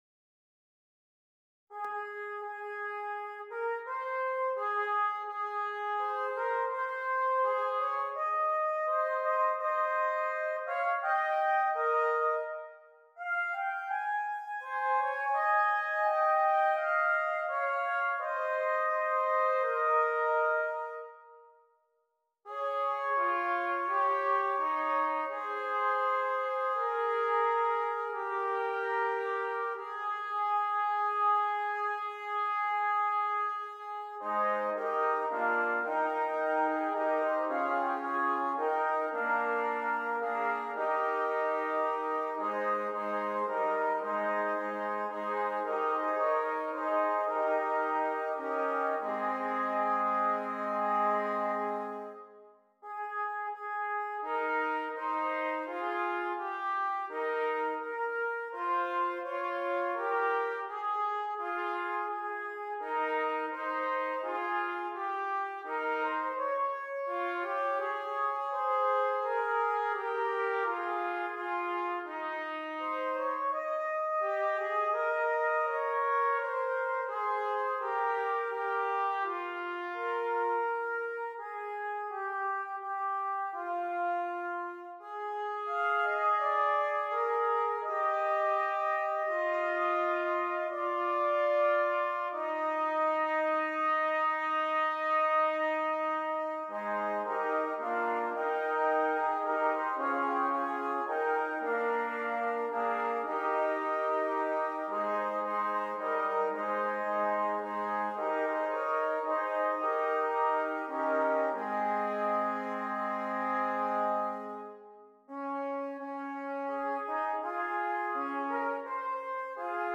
Brass
5 Trumpets